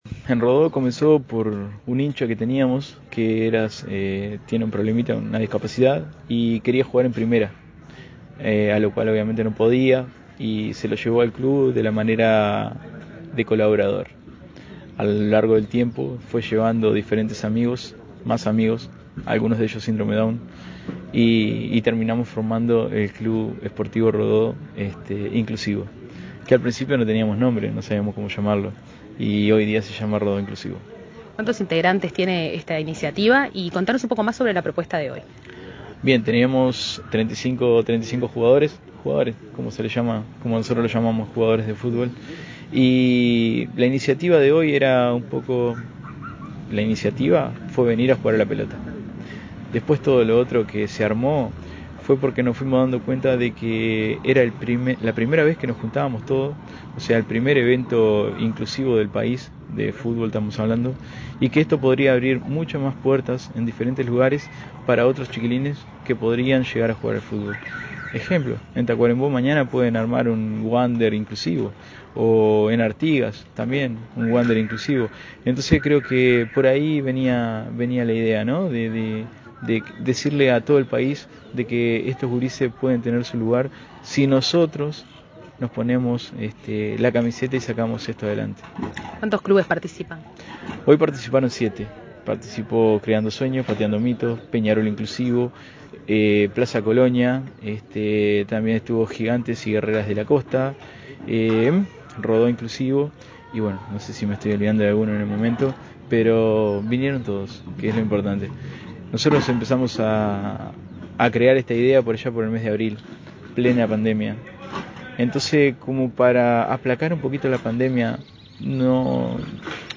Tras el encuentro de fútbol inclusivo realizado en el departamento de Salto
Entrevista